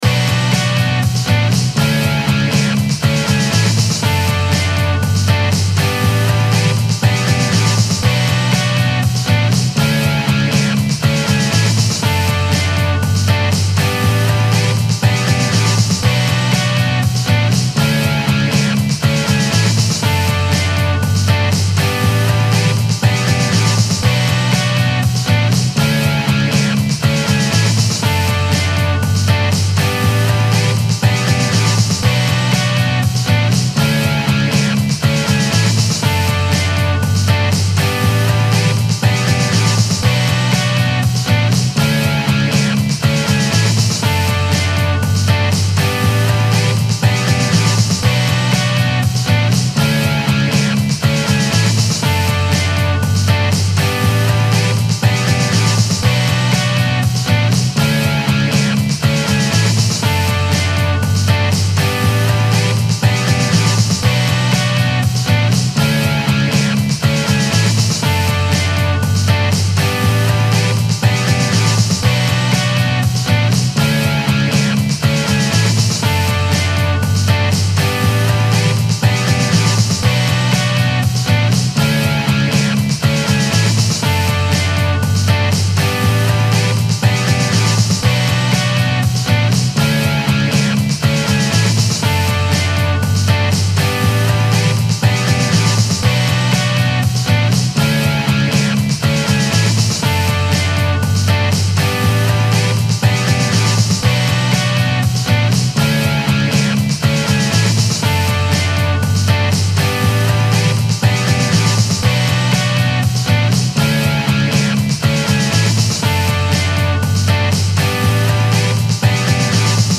Home > Music > Rock > Running > Restless > Pop